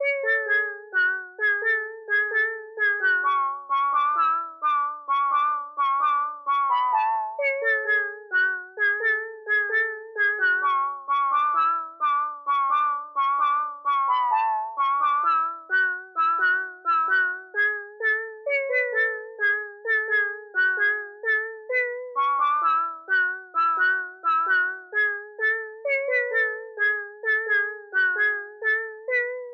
描述：一些铅弹，有一种奇怪的，但很好的，扭曲的感觉。
标签： 65 bpm Hip Hop Loops Bells Loops 4.97 MB wav Key : Unknown FL Studio
声道立体声